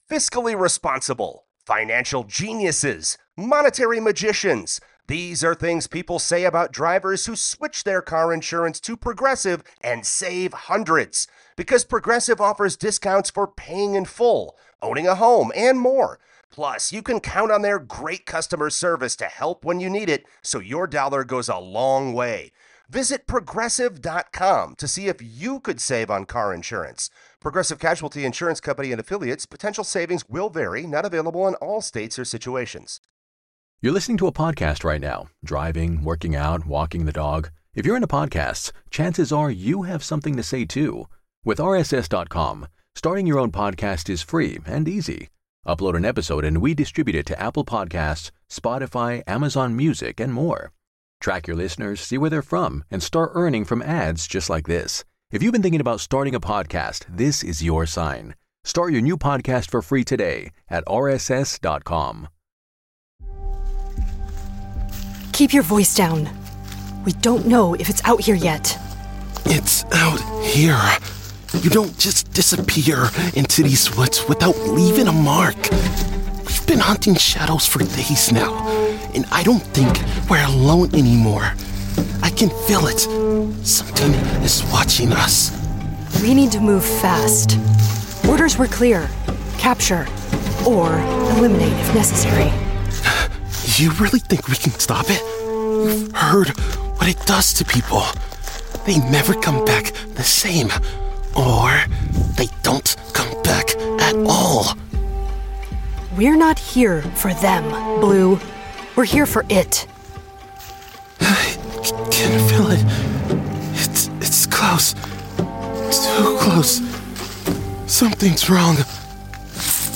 Welcome to Tales from the Janitor — a horror podcast and immersive audio drama exploring South Dakota urban legends, paranormal folklore, and chilling supernatural stories whispered across the plains.